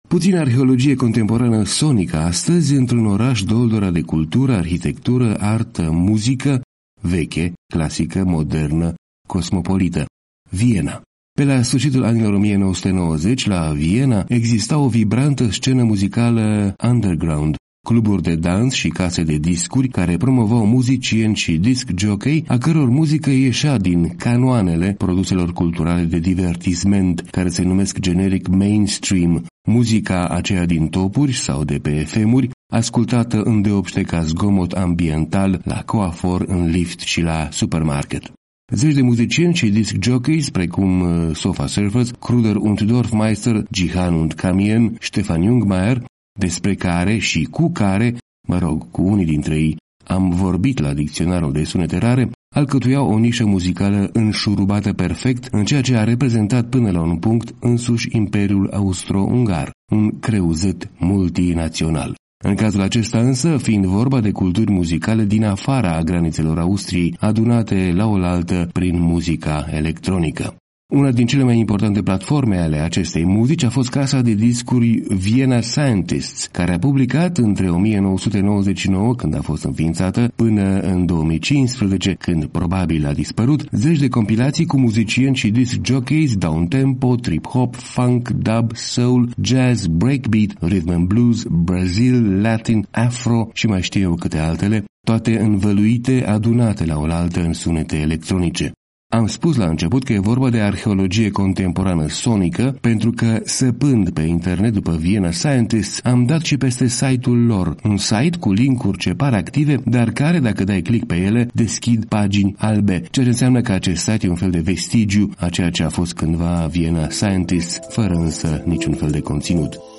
Una din cele mai importante platforme a acestei muzici a fost casa de discuri Vienna Scientists, care a publicat între 1999, când a fost înființată, pînă în 2015, când probabil a dispărut, zeci de compilații cu muzicieni și disc-jockey downtempo, trip-hop, funk, dub, soul, jazz, breakbeat, rythm’n’blues, Brazil, latin, afro, și mai știu eu cîte altele, toate învăluite, adunate laolaltă în sunete electronice.